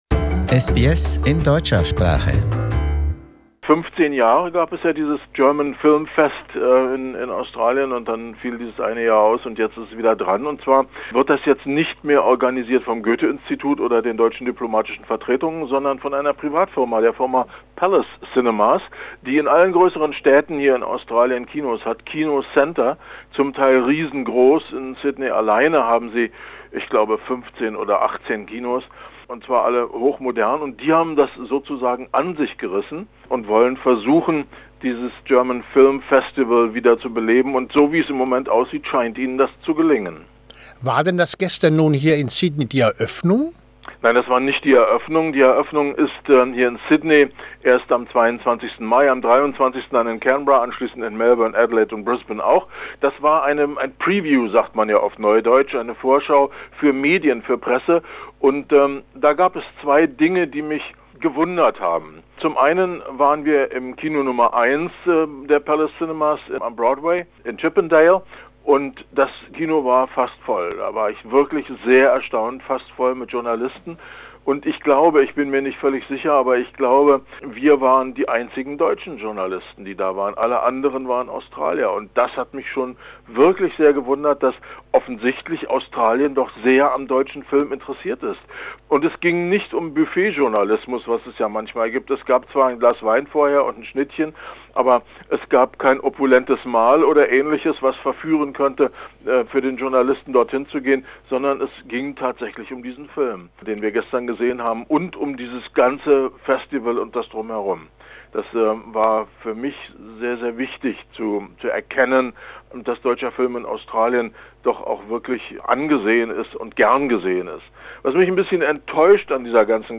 Neues 'German Film Festival": ein Kritiker spricht